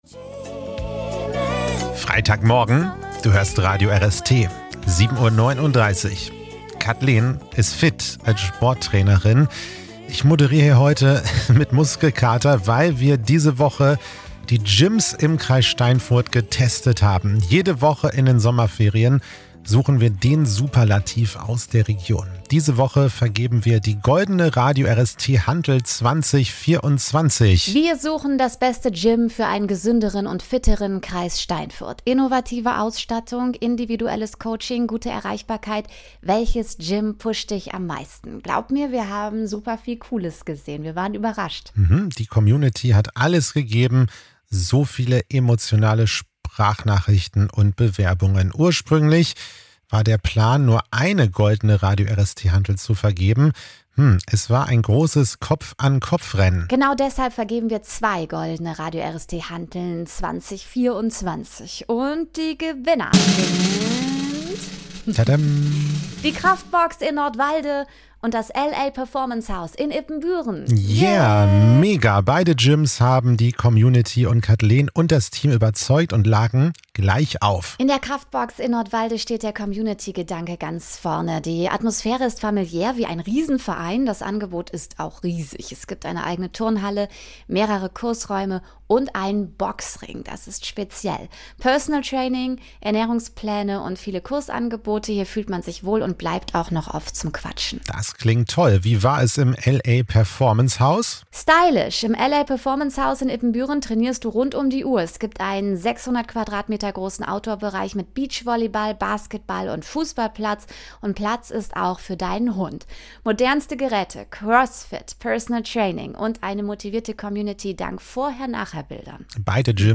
Gewinnertalk 1